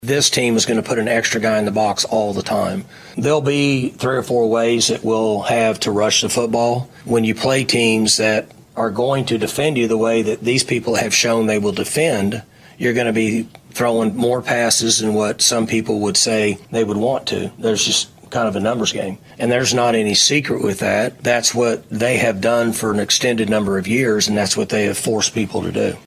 Oklahoma State head football coach Mike Gundy met with the media on Monday to talk about his club’s first matchup with Utah as a Big 12 member.
Gundy on Utah Defense 9-17.mp3